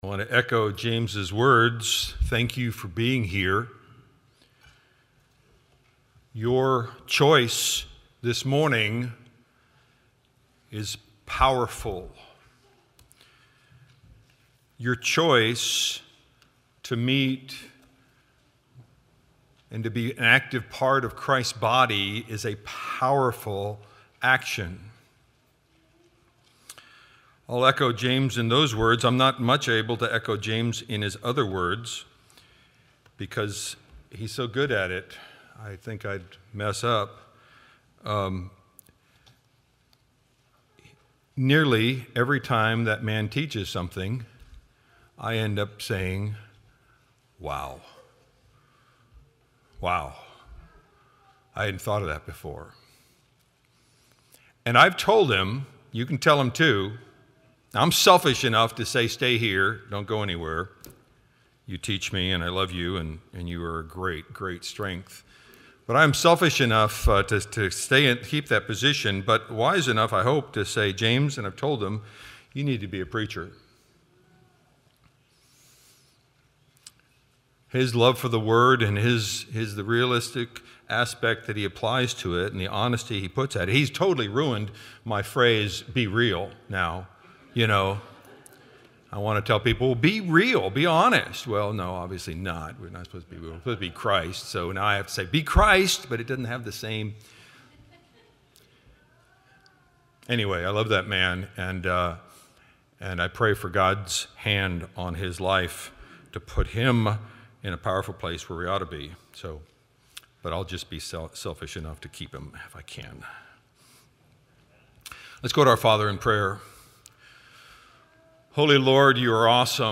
Audio Sermons Noah